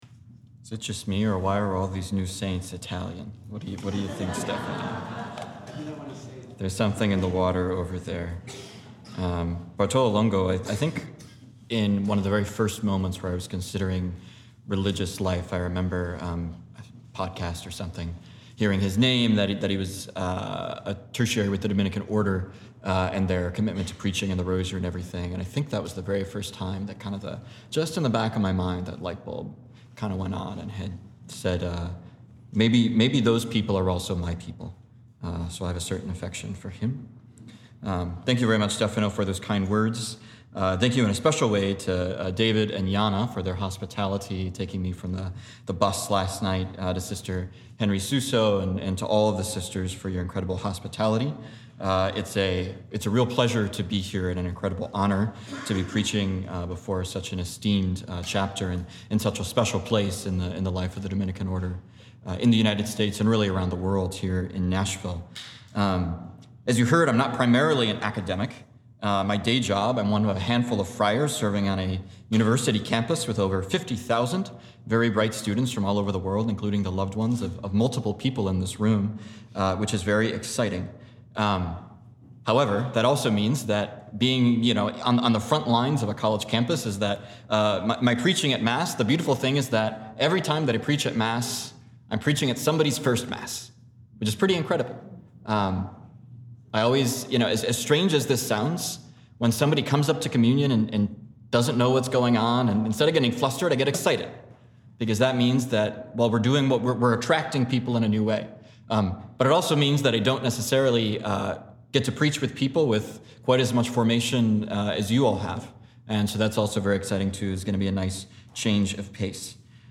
2025 Day of Recollection – Conference 1